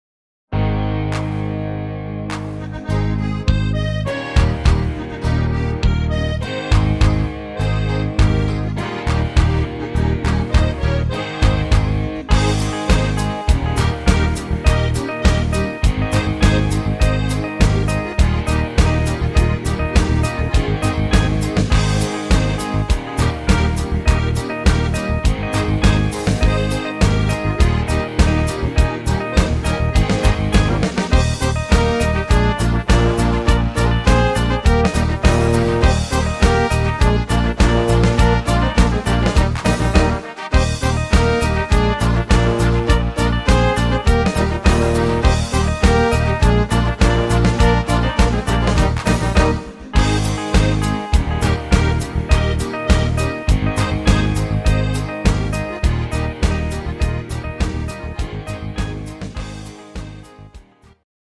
Volkstümlicher Powermix